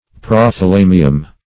Prothalamion \Pro`tha*la"mi*on\, Prothalamium